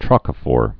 (trŏkə-fôr)